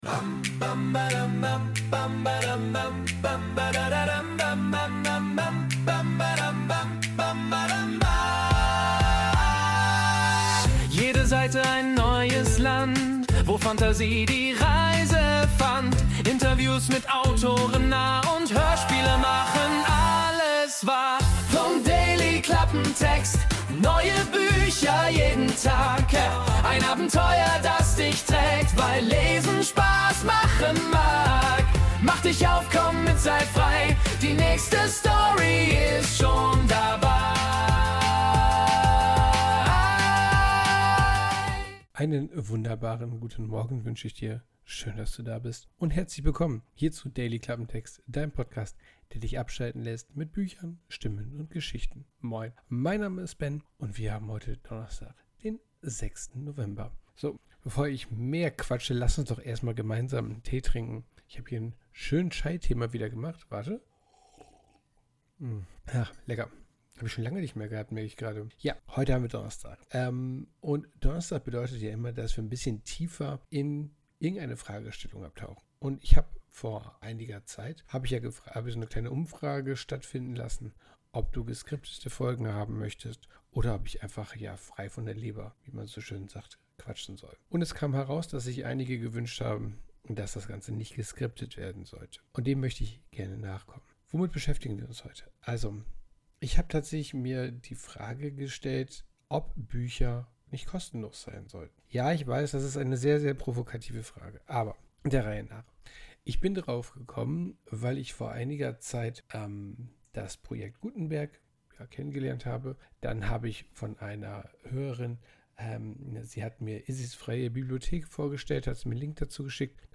Guten Morgen! diesmal labere ich wieder drauflos. Ich habe mir folgende Frage gestellt: Sollten Bücher kostenlos sein?